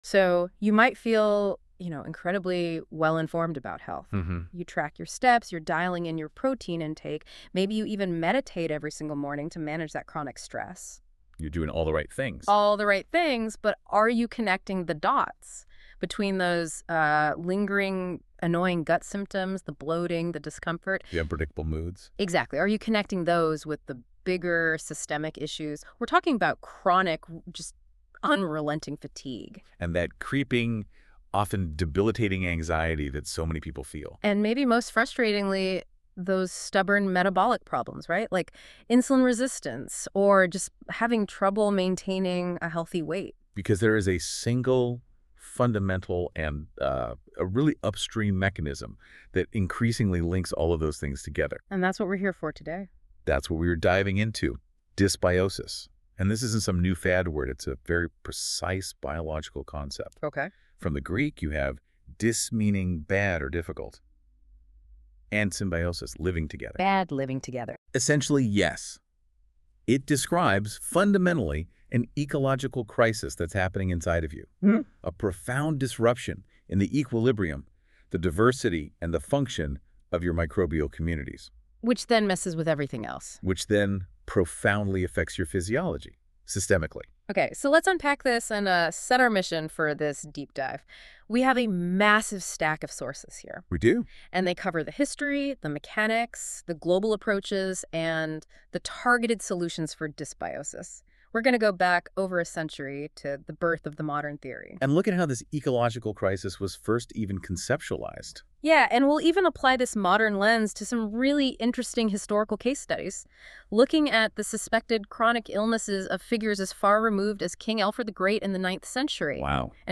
• AI Generation: These artifacts are not written by a human author but are generated by the AI analyzing the users specific sources.